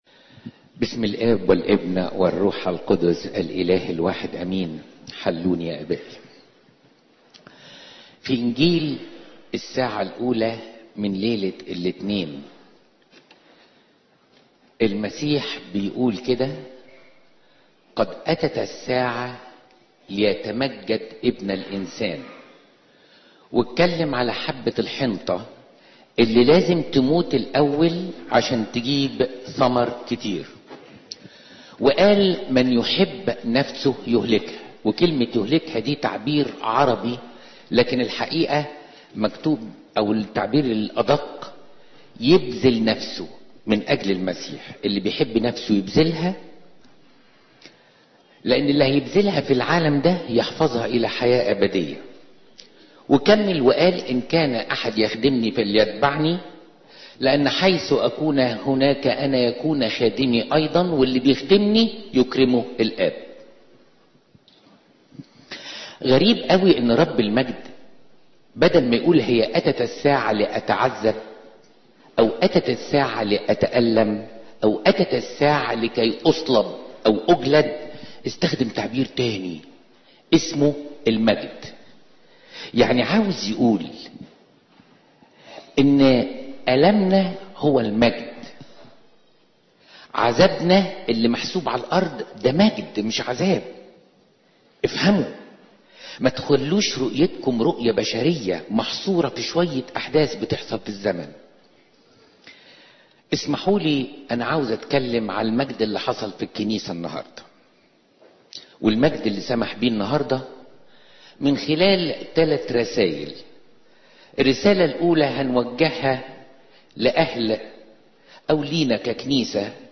عظة